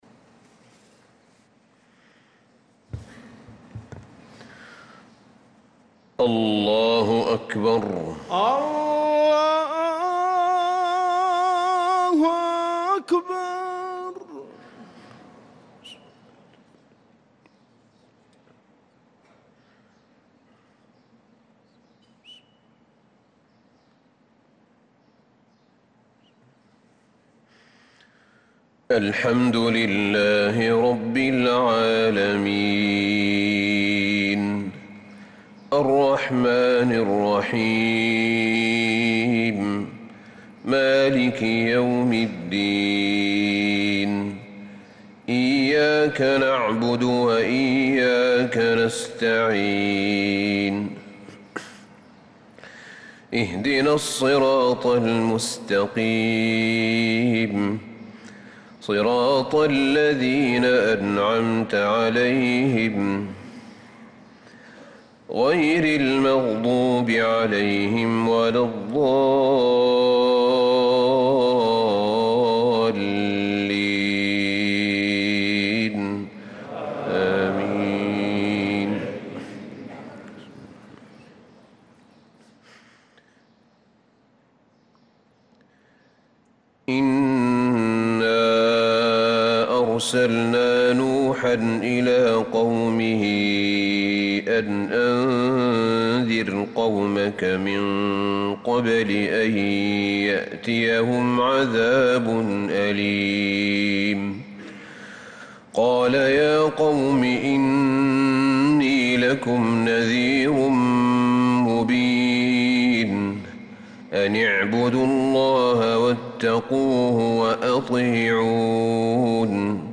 صلاة الفجر للقارئ أحمد بن طالب حميد 26 محرم 1446 هـ